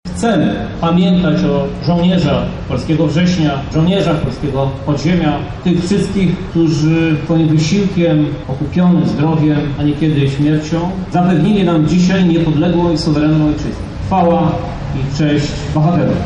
W Lublinie odbyły się uroczystości upamiętniające ofiary II wojny światowej.
-mówi Krzysztof Żuk, prezydent Lublina